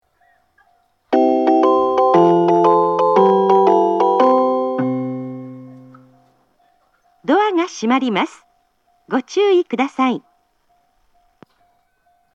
発車メロディー
一度扱えばフルコーラス鳴ります。
混線することがたまにあります。